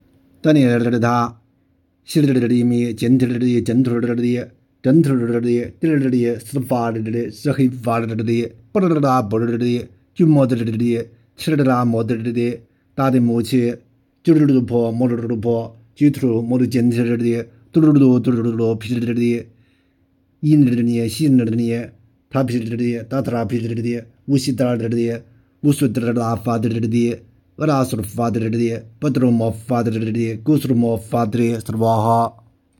恭诵
四天王护生咒.m4a